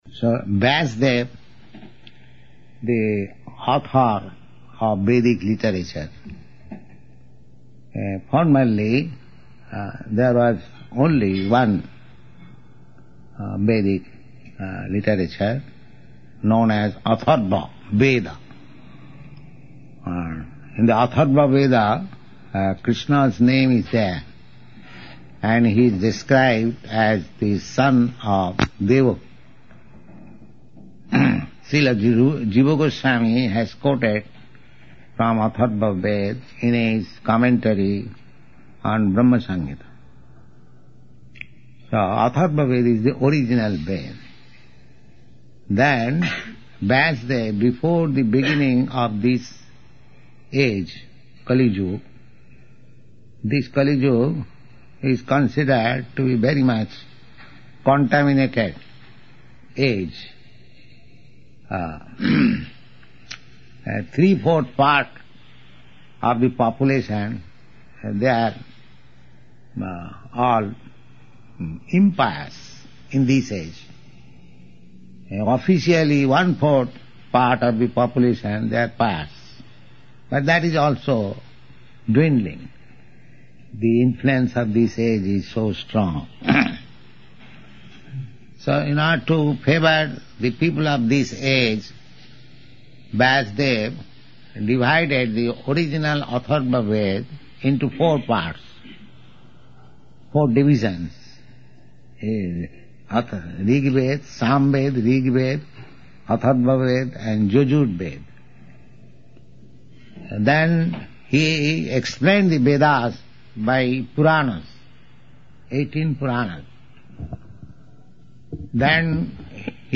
Temple Opening
Temple Opening --:-- --:-- Type: Lectures and Addresses Dated: May 9th 1969 Location: Colombus Audio file: 690509TO-COLUMBUS.mp3 Prabhupāda: So Vyāsadeva, the author of Vedic literature…